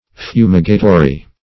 Search Result for " fumigatory" : The Collaborative International Dictionary of English v.0.48: Fumigatory \Fu"mi*ga*to*ry\, a. [Cf. F. fumigatoire.] Having the quality of purifying by smoke.
fumigatory.mp3